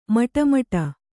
♪ maṭa maṭa